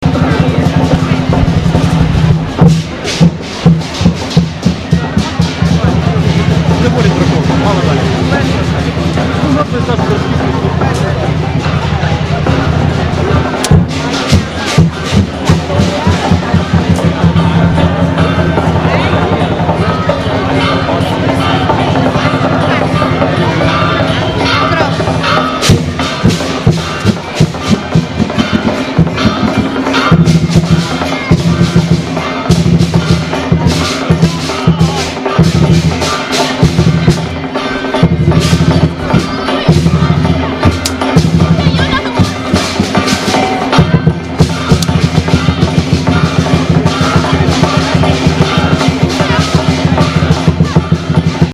LONDON—Lion-dancers leaped through thousands of people thronging the narrow streets of Chinatown, right in the heart of London's West End.
"Happy New Year to everybody! Please would you sign your name to pledge your support for our campaign—to help save Chinatown," a campaigner hailed the crowd through a megaphone against the background of beating drums and folk opera.